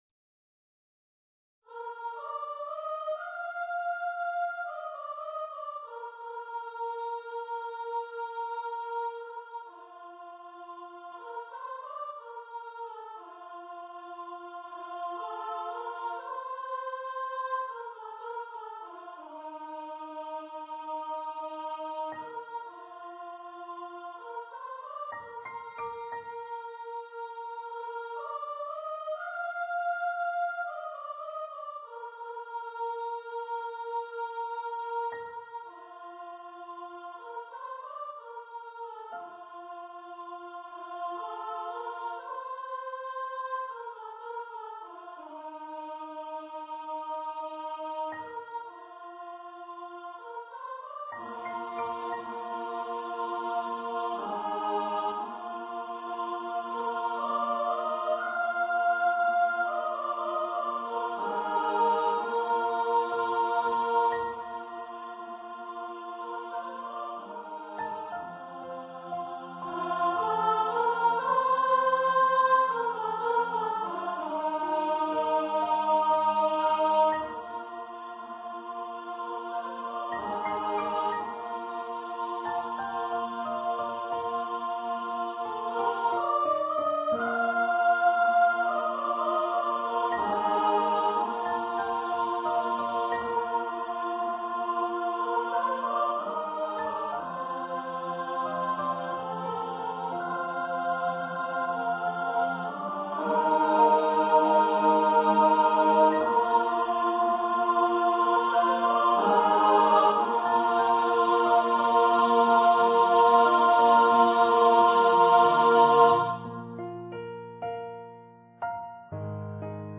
for female voice choir
Choir - 3 part upper voices